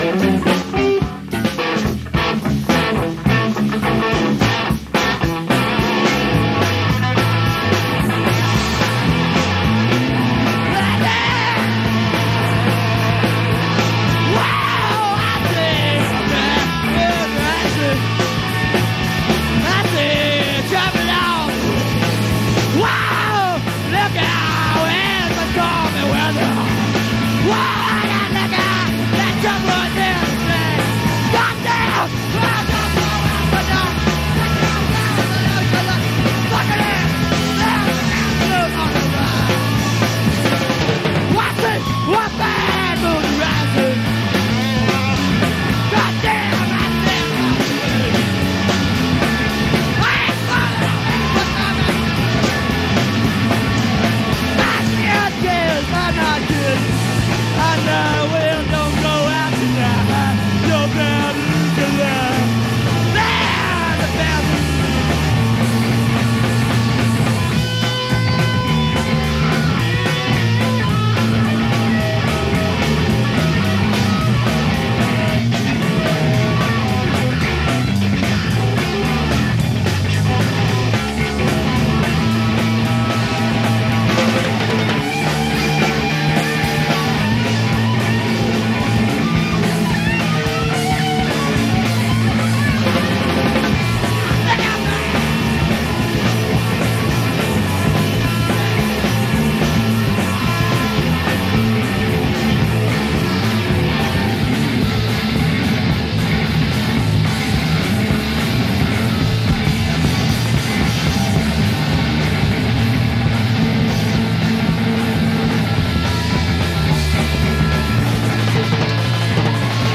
when the sound was raw